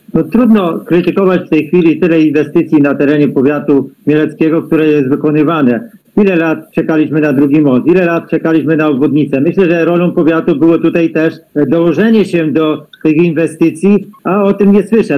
Swoje zdanie w tym temacie wyraża również radny powiatowy Antoni Skawiński.